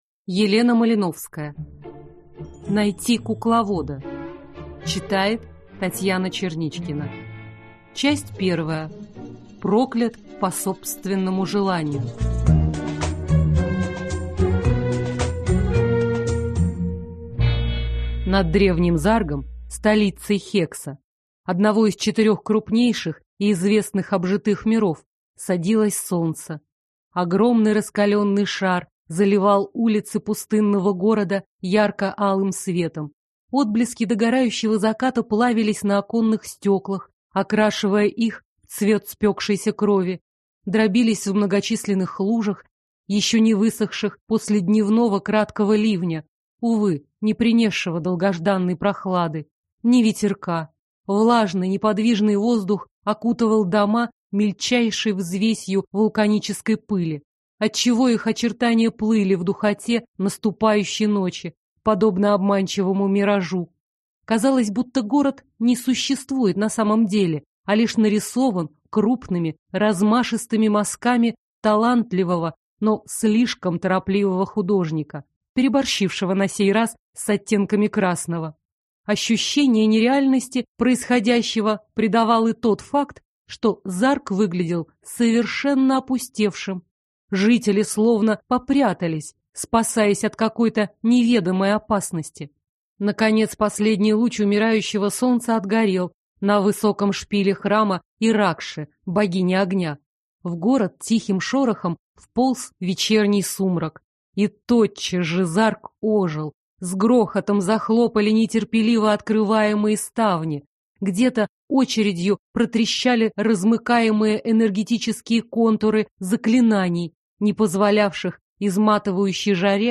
Аудиокнига Найти кукловода | Библиотека аудиокниг